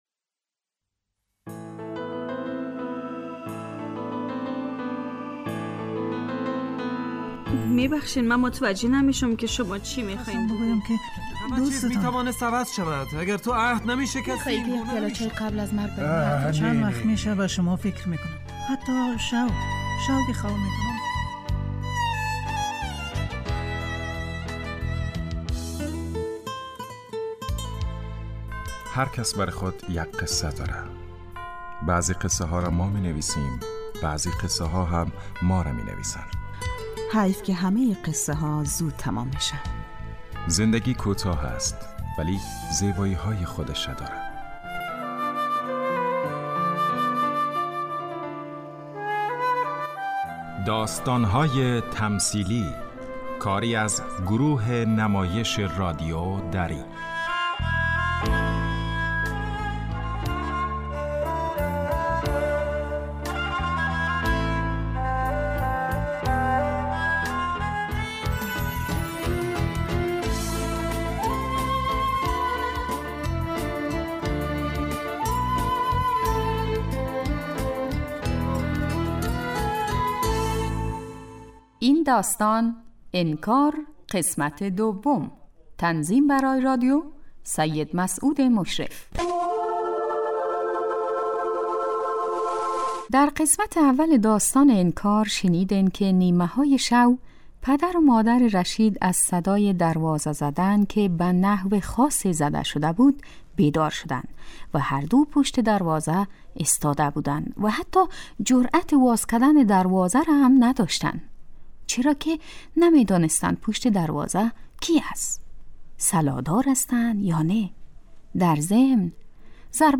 داستانهای تمثیلی نمایش 15 دقیقه ای هستند که هر روز ساعت 3:30 عصربه وقت وافغانستان پخش می شود.